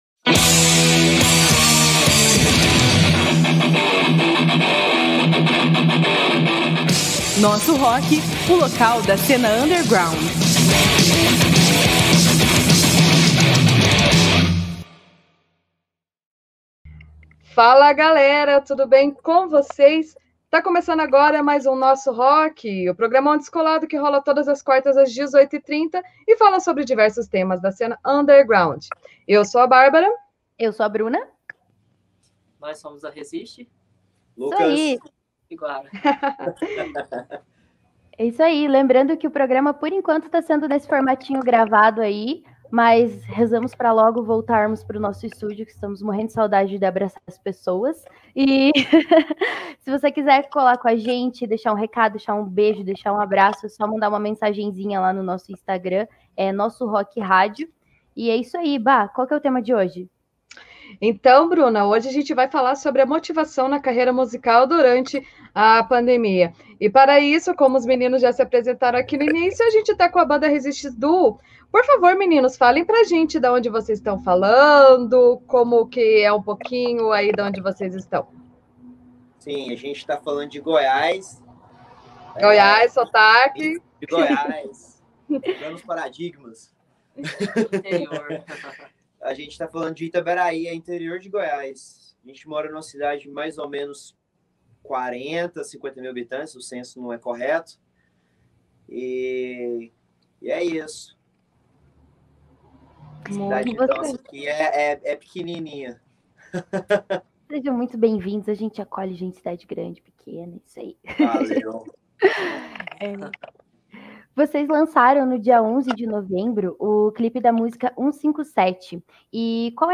A edição da vez do programa Nosso Rock recebe a banda ResistDuo! Rolou uma conversa muito boa sobre os trabalhos da dupla e o que vem por aí.